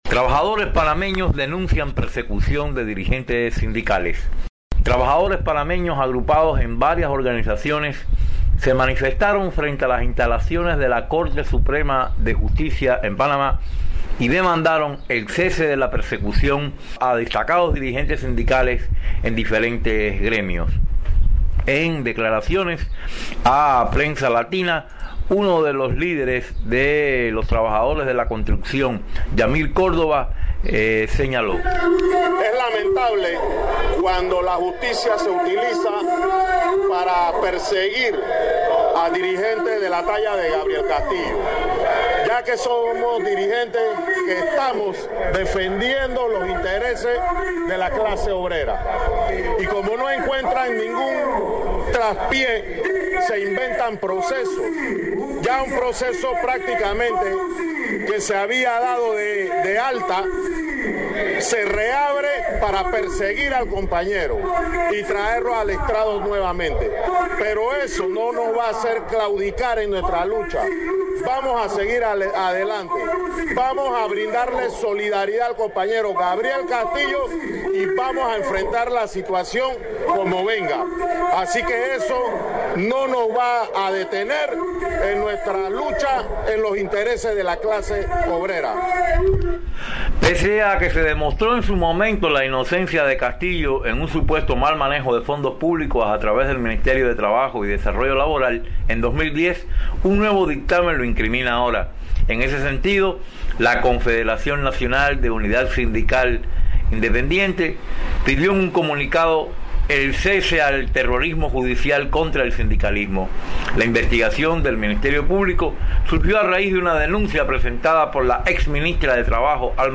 desde Ciudad de Panamá